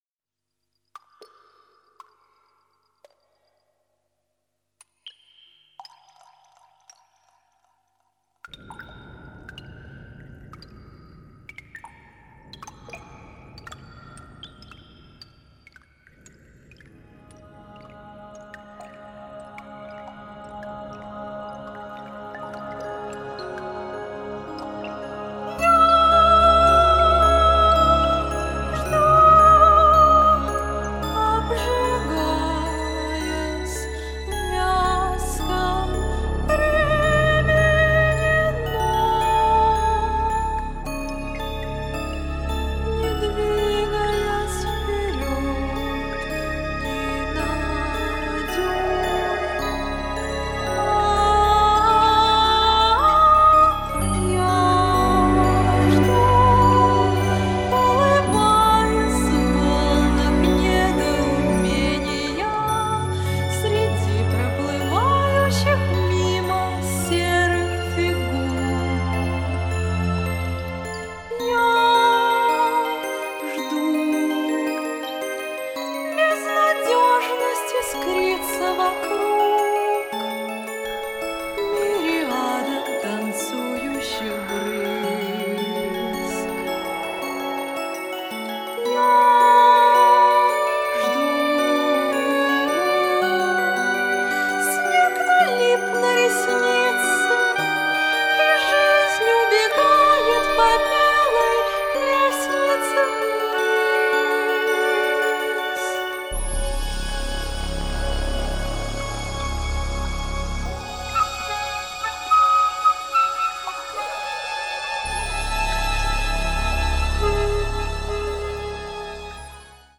"неоклассика" - от академизма до прогрессивного фолка.